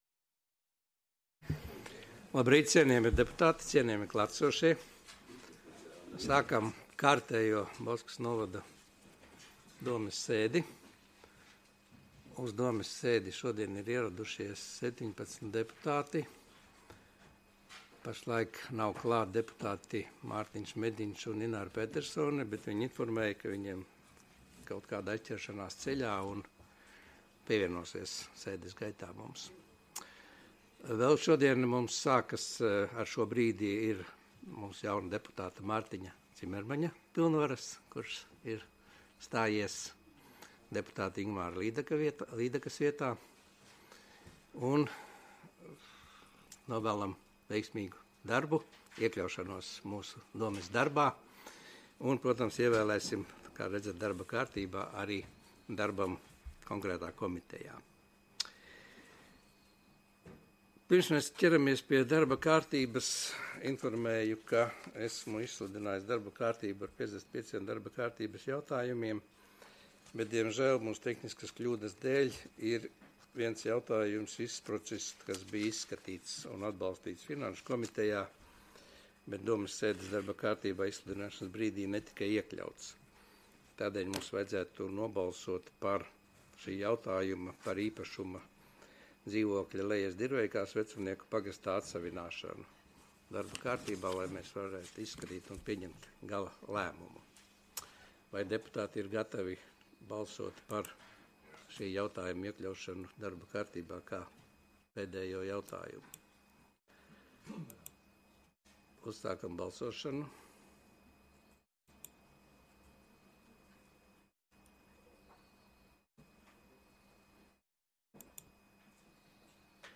Audioieraksts - 2022.gada 24.novembra domes sēde